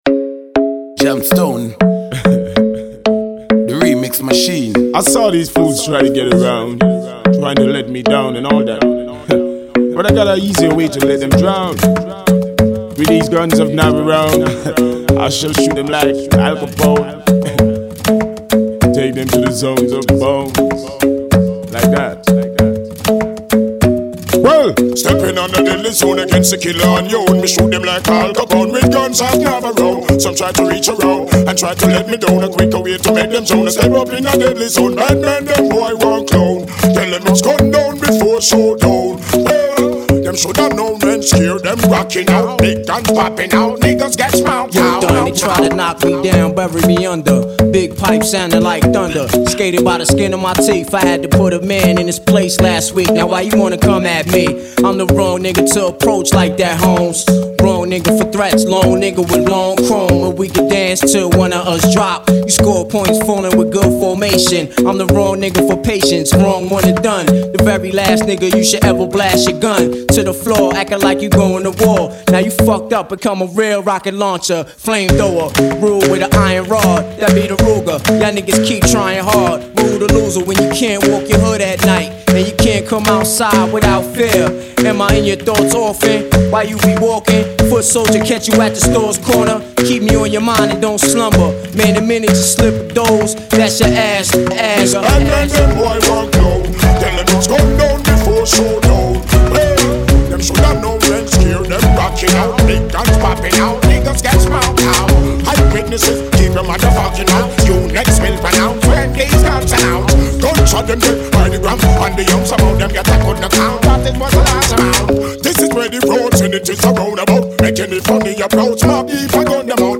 Vocal track